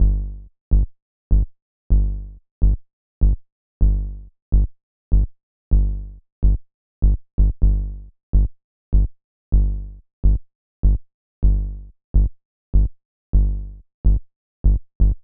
• Punchy Tech House Bass Rhythm - EDM - Gm - 126.wav
Punchy_Tech_House_Bass_Rhythm_-_EDM_-_Gm_-_126_4ua.wav